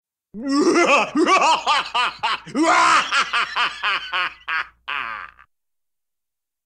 File:Evil Laugh.oga
Sound effect used for King K. Rool and the resident demon in Donkey Kong 64.
Evil_Laugh.oga.mp3